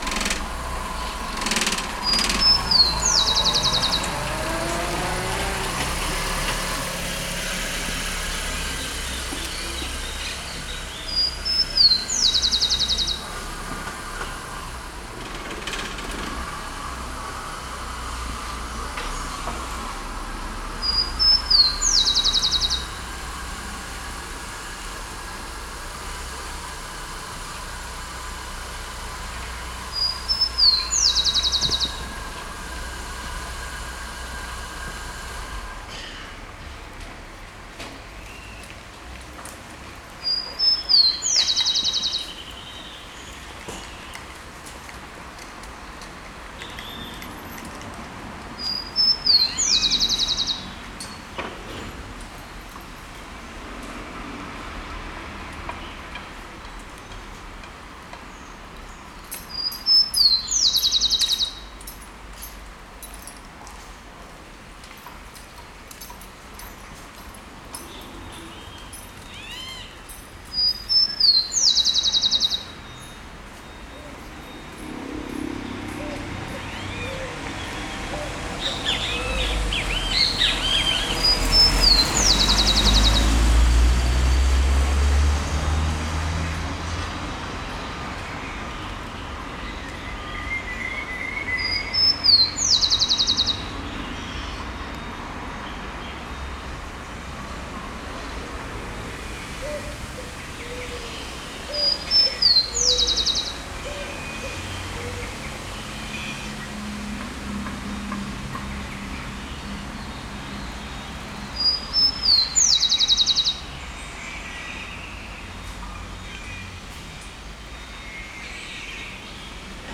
Ciudad de Rosario 9 hs. 11 de Noviembre 2025